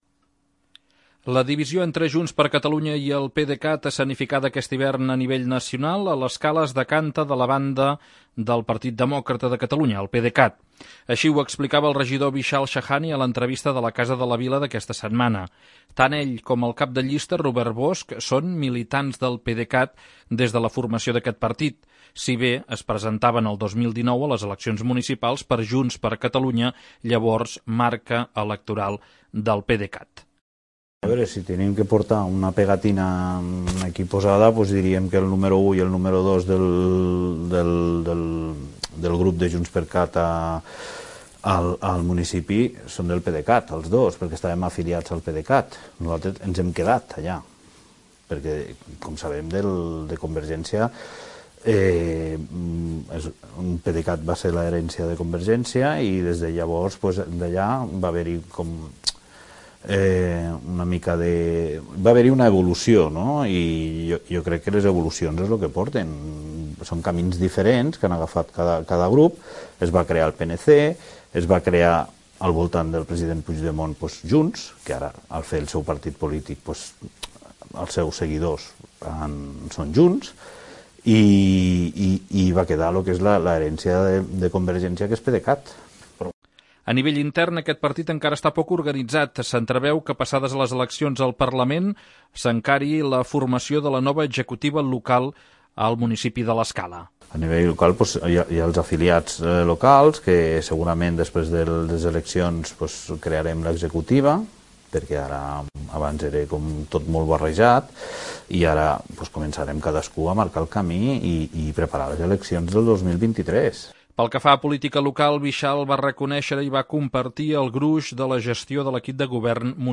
Així ho va explicar el regidor Vishal Shahani a l'entrevista de La Casa de la Vila d'aquesta setmana.